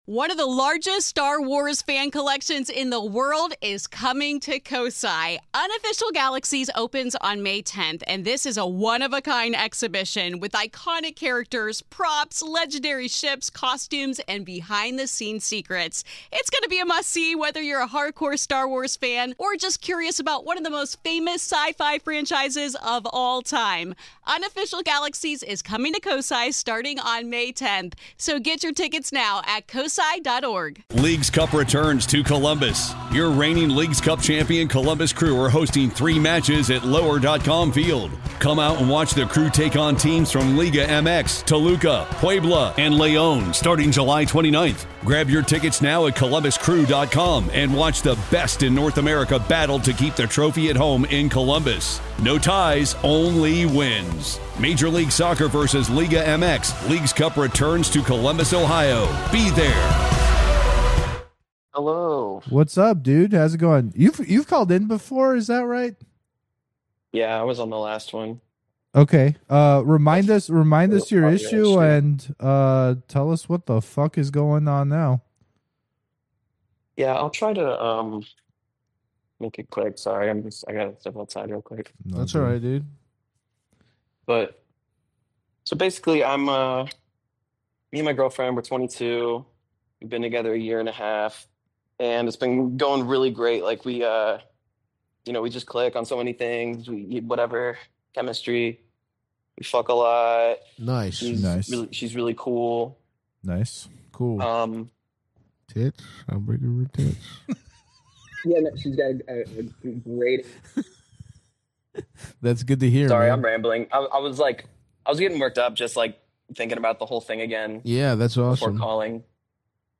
Bonus #127 - Live Call Show Vol. 24 [PATREON PREVIEW]